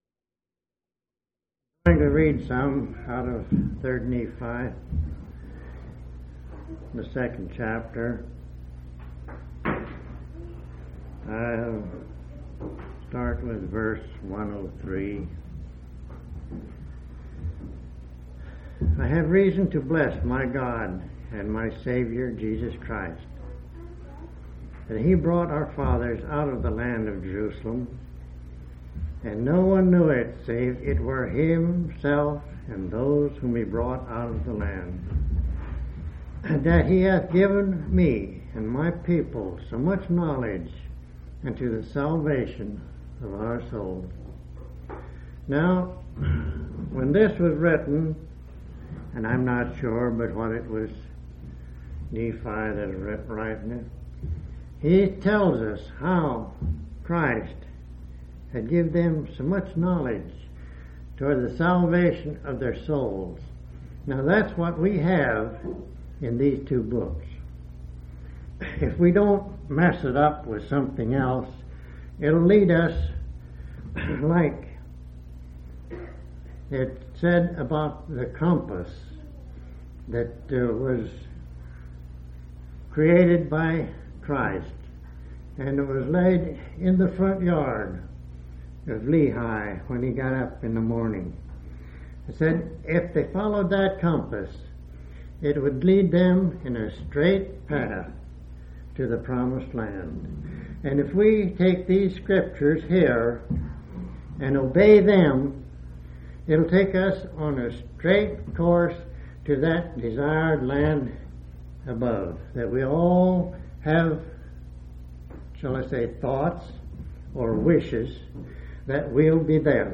9/25/1983 Location: Grand Junction Local Event